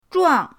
zhuang4.mp3